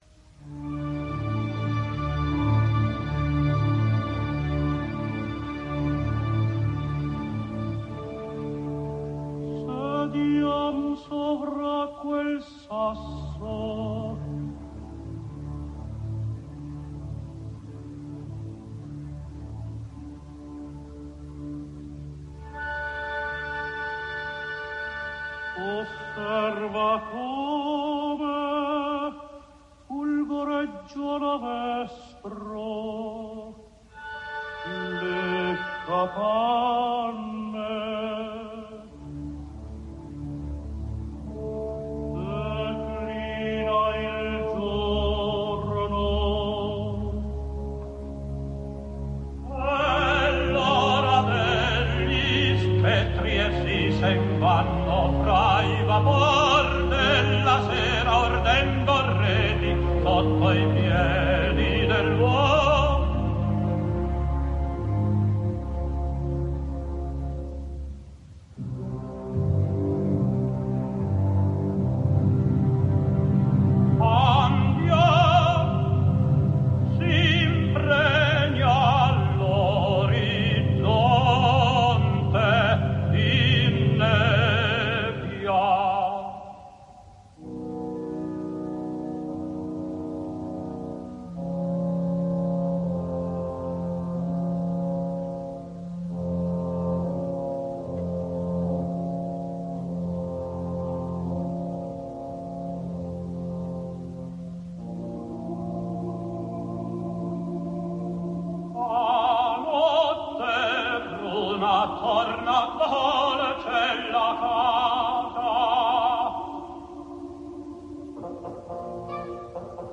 registrazione dal vivo.
Osserva come Faust, Wagner, Coro